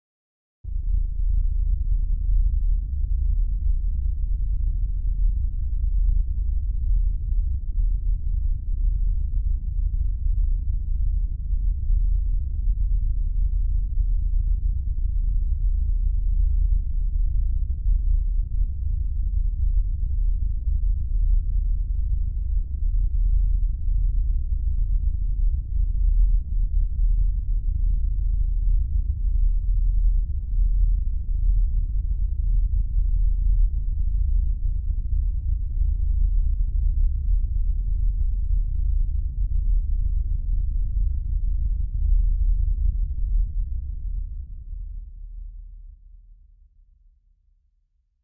宇宙飞船" 宇宙飞船隆隆声bg4
描述：用vst乐器albino制作
Tag: 未来 无人机 驱动器 背景 隆隆声 黑暗 冲动 效果 FX 急诊室 悬停 发动机 飞船 氛围 完善的设计 未来 空间 科幻 电子 音景 环境 噪音 能源 飞船 大气